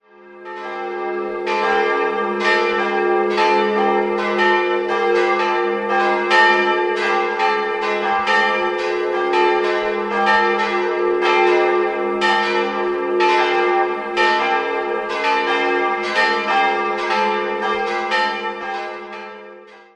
Die große Glocke wurde von Hans Glockengießer (III) Mitte des 16. Jahrhunderts in Nürnberg gegossen, die mittlere stammt von Karl Czudnochowsky (Heidingsfeld) aus dem Jahr 1950 und die kleine ist ein Werk von Joseph Stapf (Eichstätt) aus dem Jahr 1803.